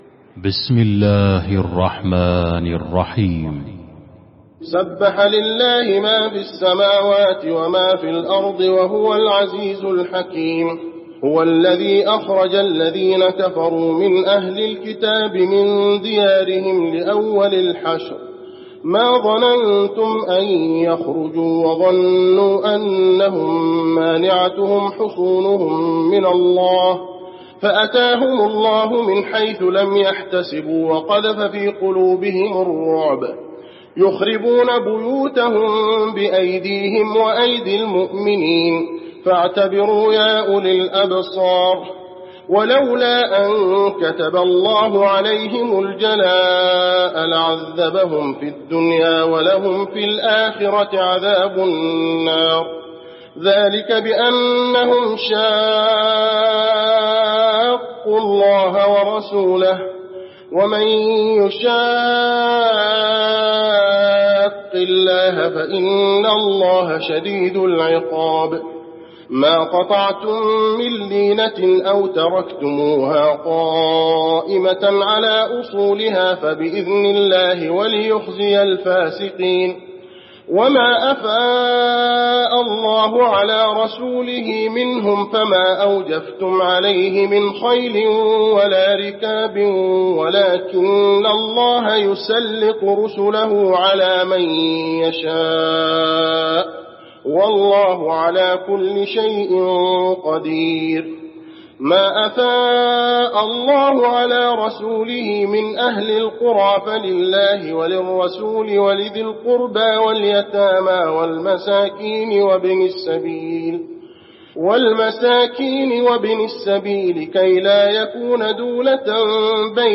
المكان: المسجد النبوي الحشر